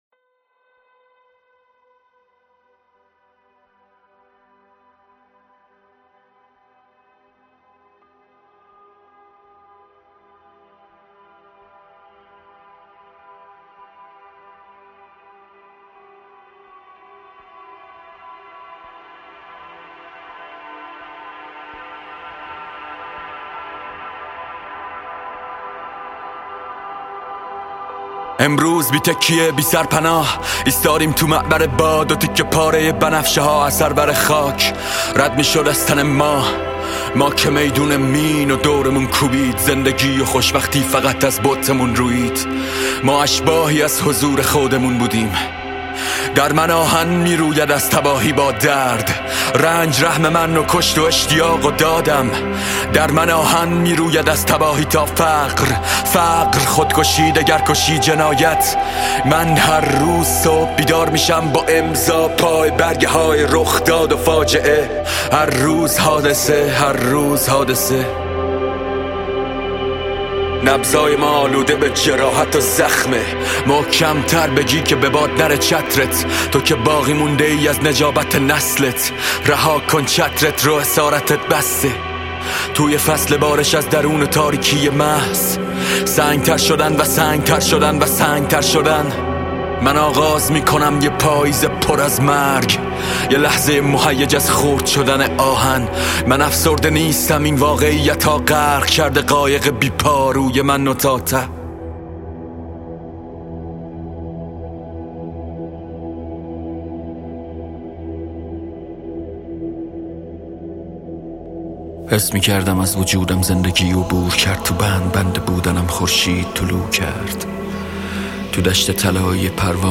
رپ فارسی
سبک هیپ هاپ